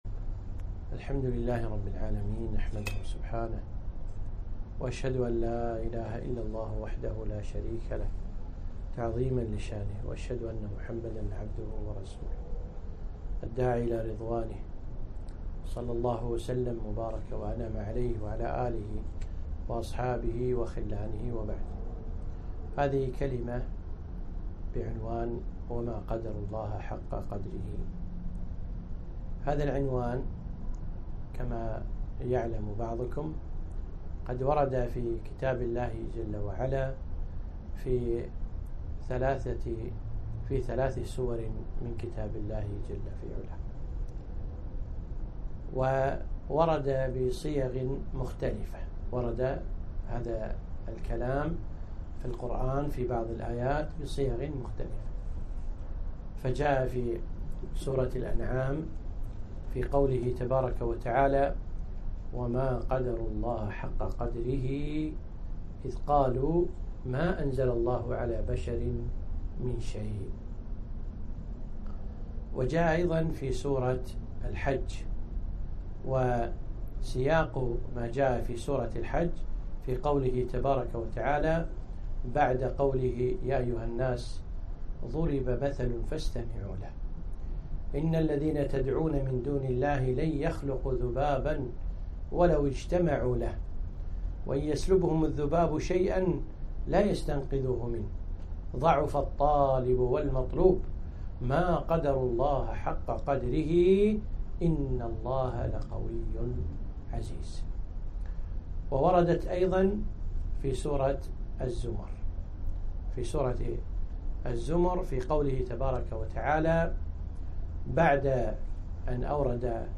محاضرة - وما قدروا الله حق قدره